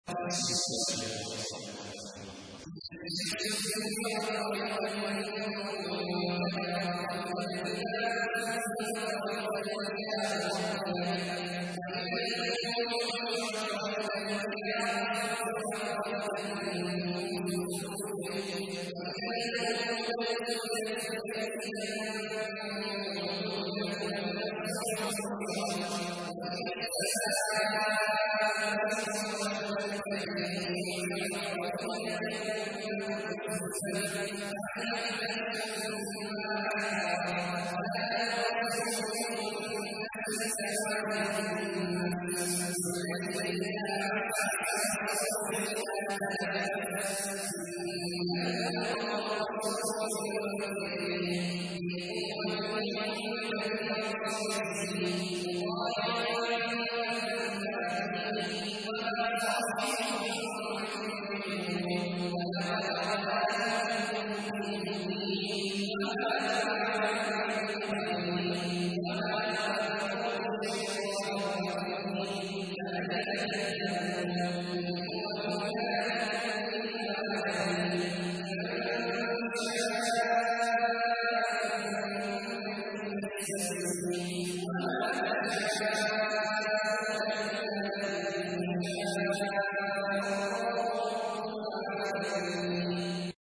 تحميل : 81. سورة التكوير / القارئ عبد الله عواد الجهني / القرآن الكريم / موقع يا حسين